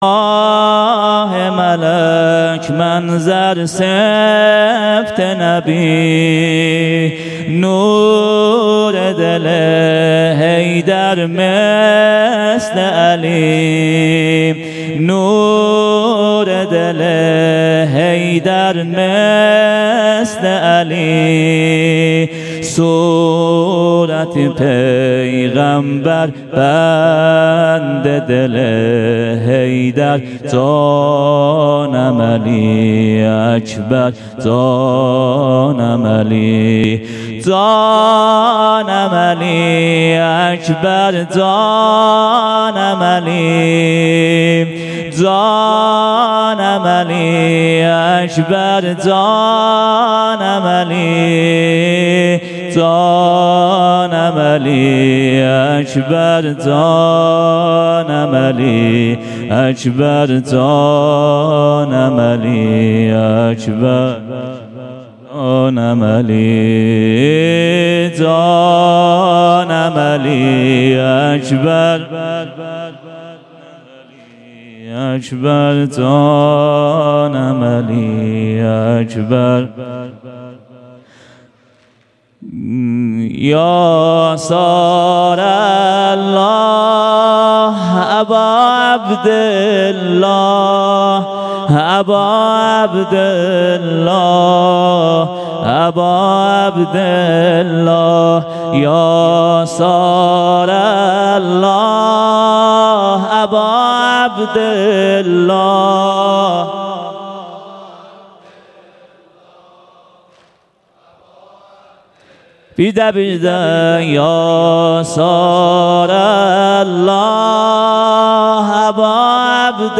محرم ۹۵. شب هشتم ( بخش اول سینه زنی)